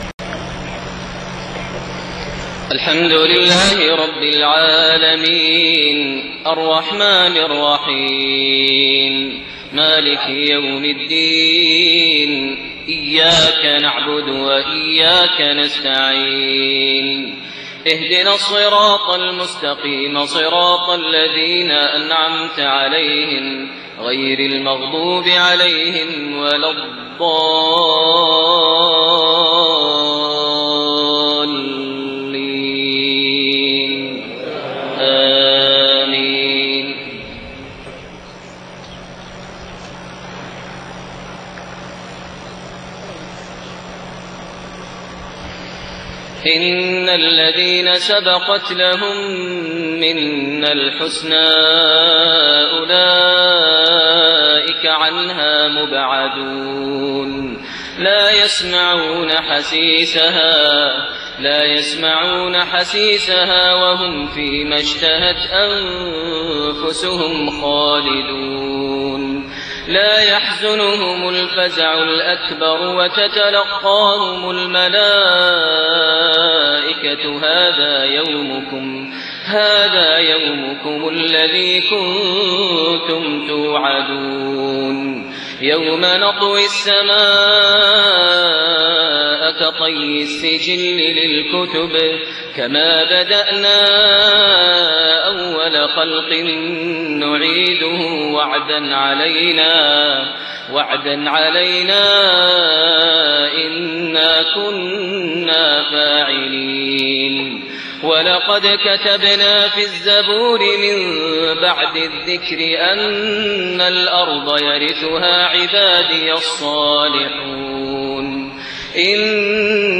Maghrib prayer Surat Al-Anbiyaa > 1429 H > Prayers - Maher Almuaiqly Recitations